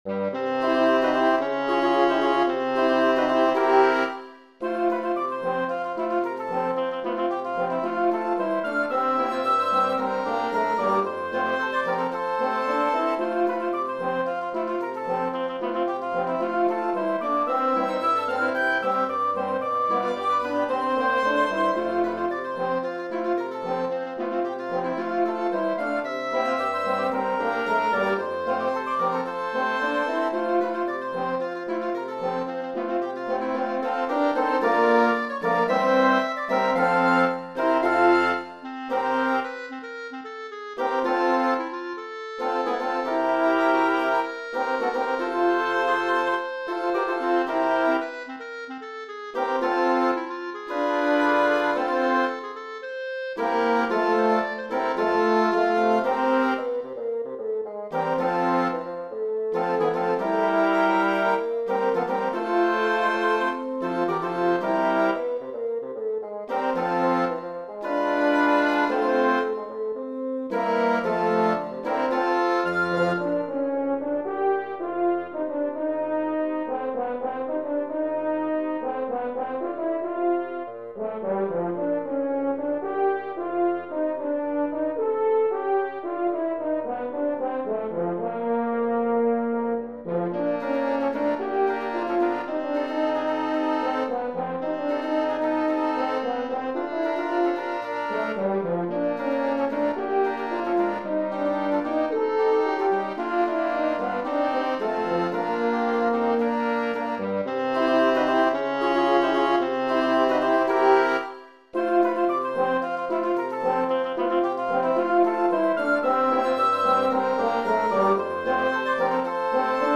Voicing: 5 Woodwinds